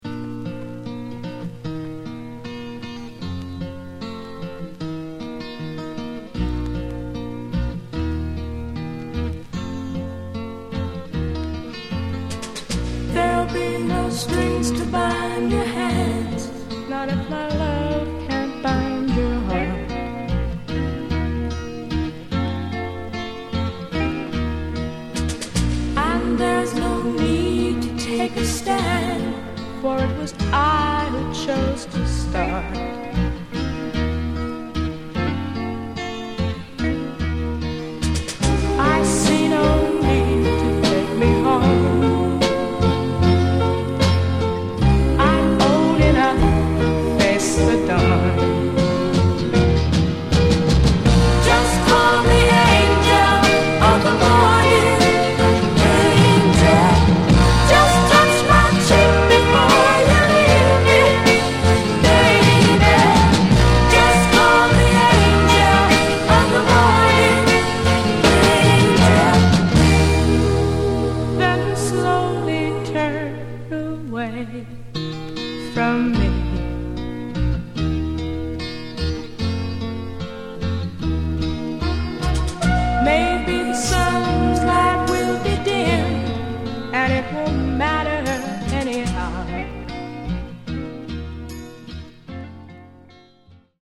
Genre: Girl Group
a delicate and powerful rendition